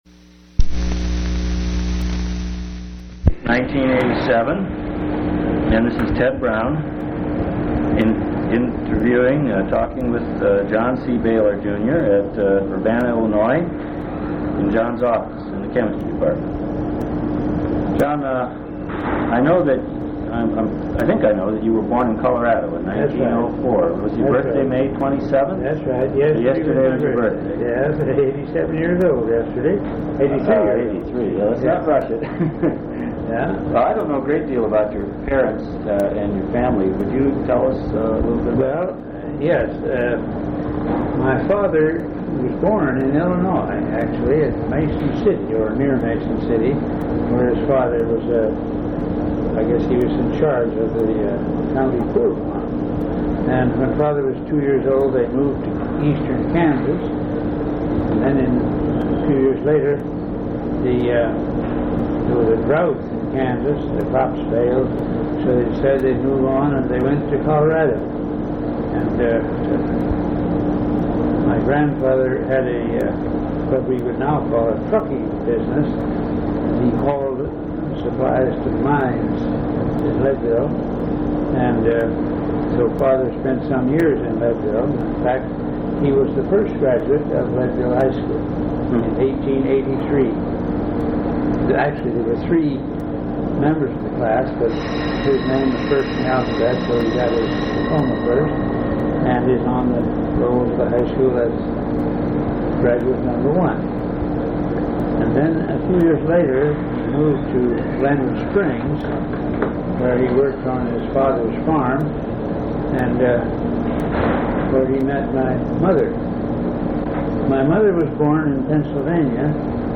Oral history interview with John C. Bailar, Jr.
Place of interview University of Illinois at Urbana-Champaign